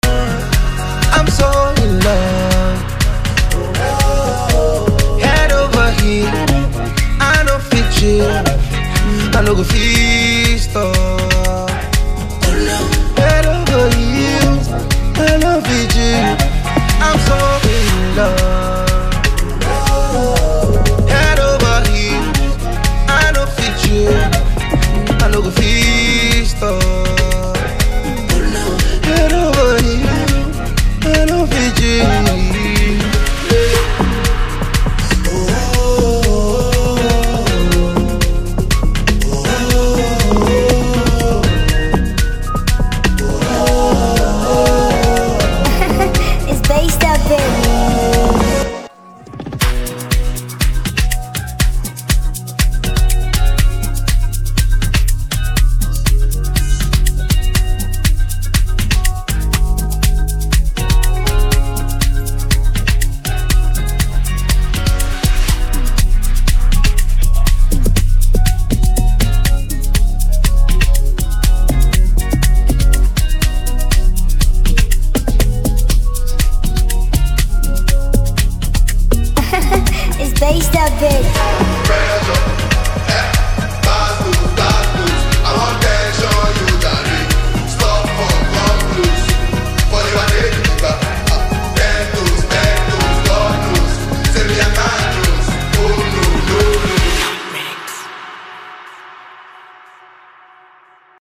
Download instrumental mp3 below…